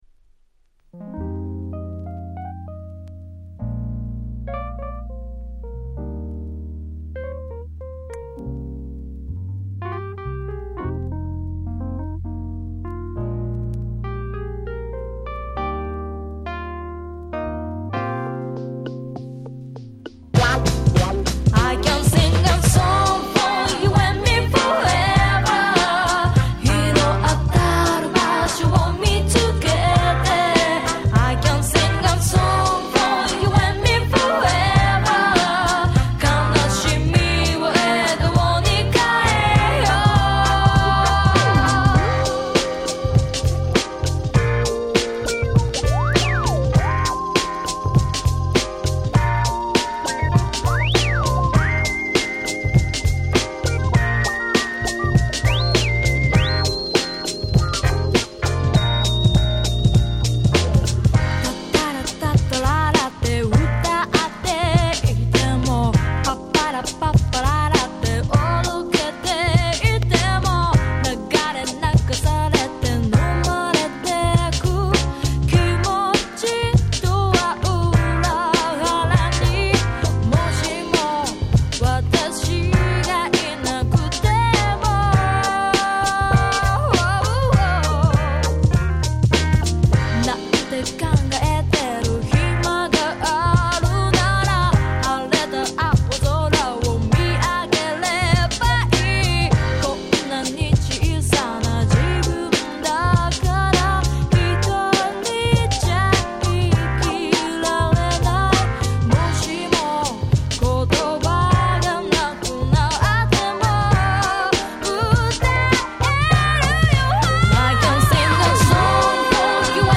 98' Big Hit Japanese R&B !!
緩いバラードだった前作に打って変わって、こちらはフロア対応のMidダンスナンバー！
J-R&B